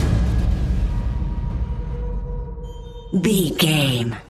Scary Punch.
Atonal
scary
ominous
eerie
synthesiser
viola